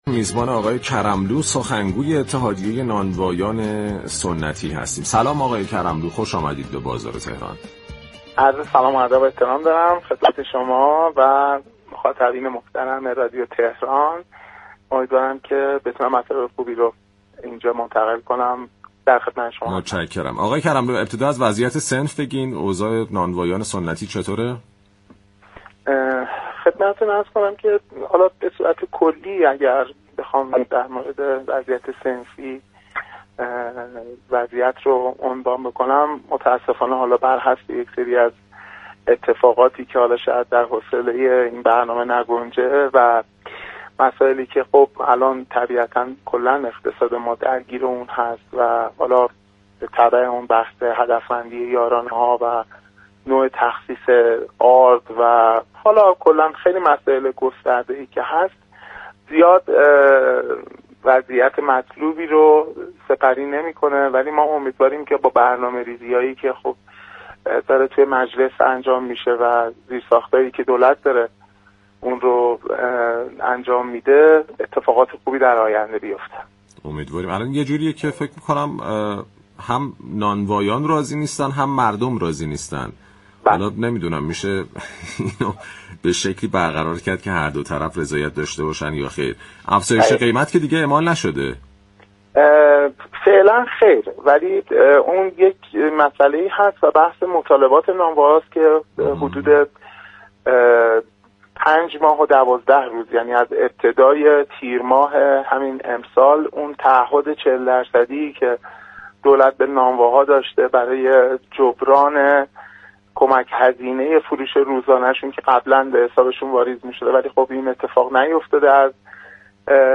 برنامه«بازار تهران»، روزهای شنبه تا چهارشنبه 11 تا 11:55 از رادیو تهران پخش می‌شود.